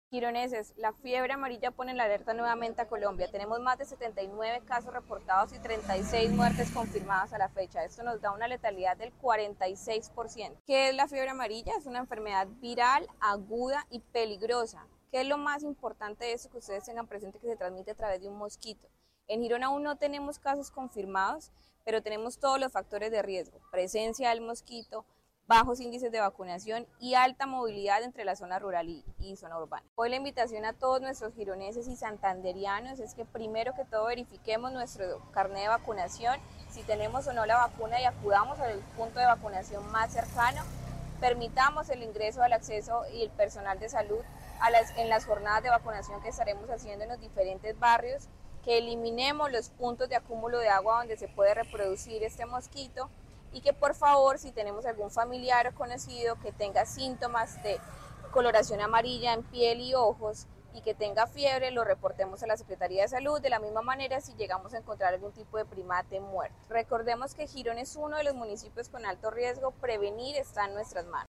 Camila Borrero - Secretaria Municipal de Salud.mp3